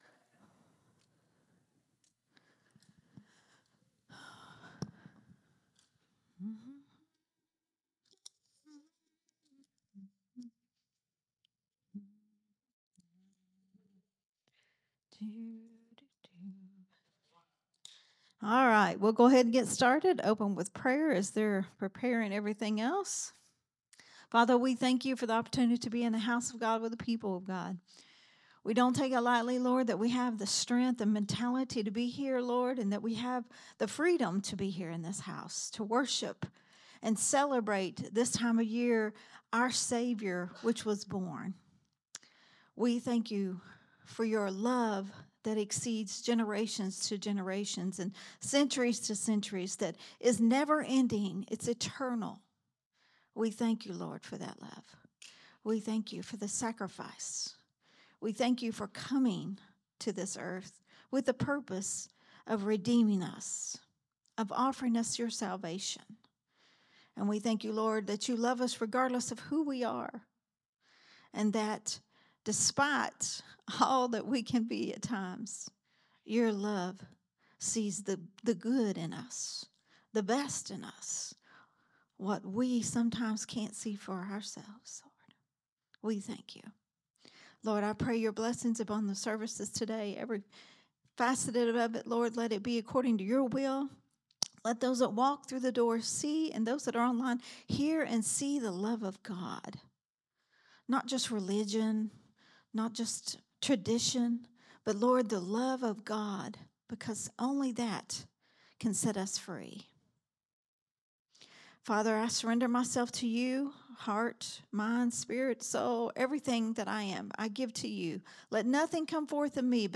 recorded at Growth Temple Ministries on Sunday, December 21, 2025.